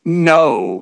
synthetic-wakewords
ovos-tts-plugin-deepponies_Discord_en.wav